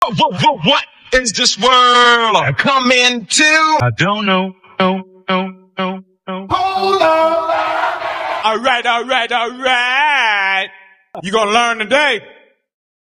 deep-voiced, New York–sounding